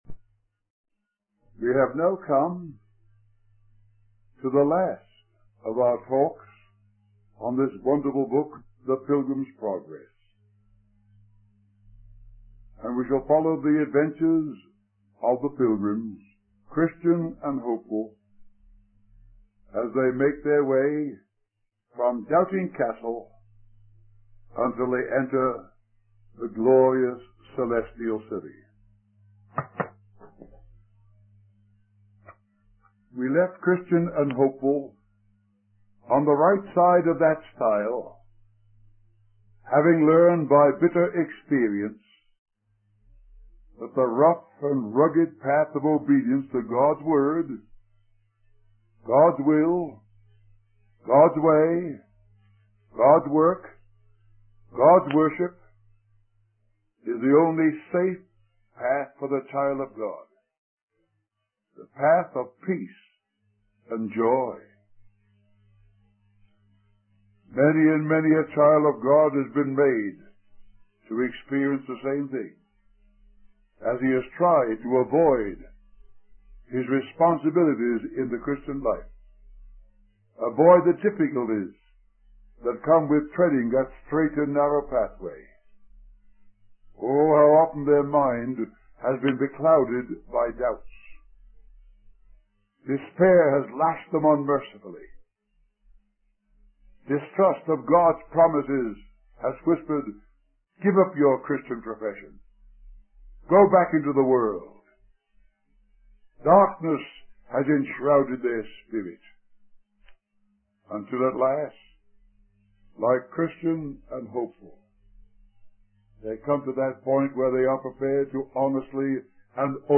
In this sermon, the preacher discusses the concept of shepherds in the assembly of the Lord's people. He explains that these shepherds are chosen by God to guide and feed the flock based on their knowledge of the word of God, watchfulness in the things of God, experience of God's ways, and sincerity in the way of God. The preacher emphasizes the importance of younger members in the assembly listening to and learning from their elder brethren, who have a longer experience of God's ways.